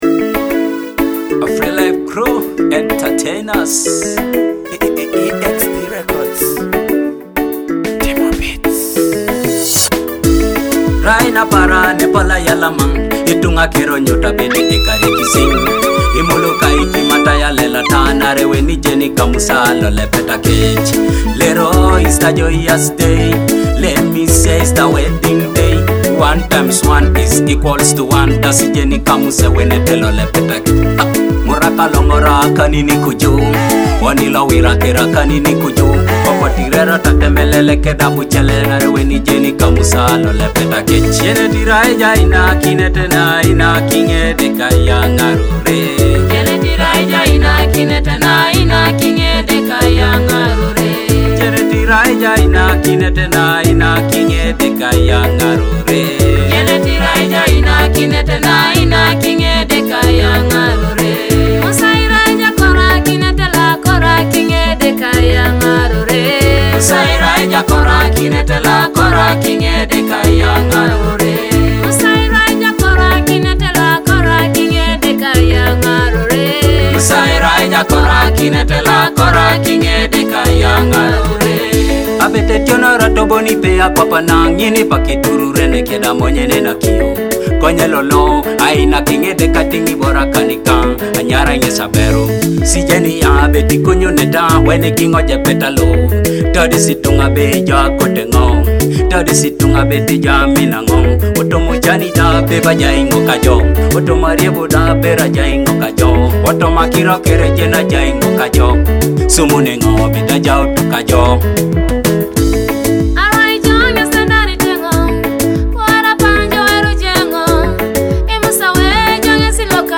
uplifting spirit